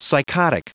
Prononciation du mot psychotic en anglais (fichier audio)
Prononciation du mot : psychotic